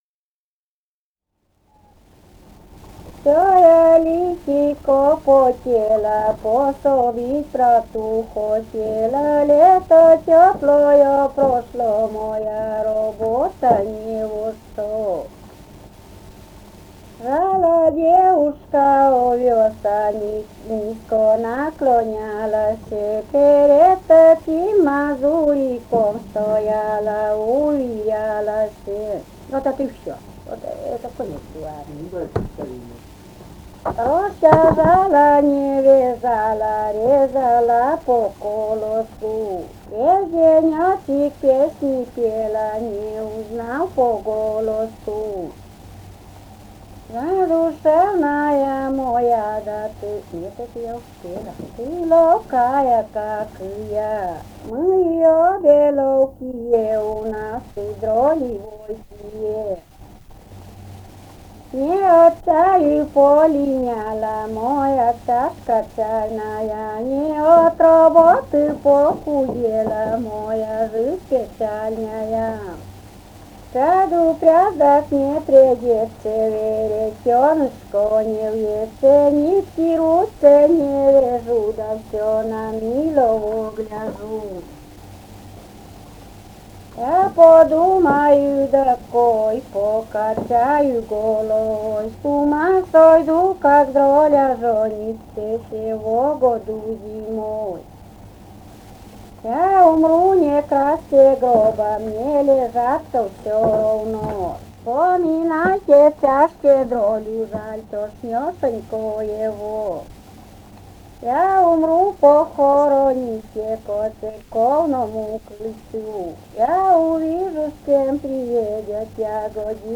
полевые материалы
«Всёя личико потело» (частушки).
Вологодская область, д. Малая Тигинского с/с Вожегодского района, 1969 г. И1129-11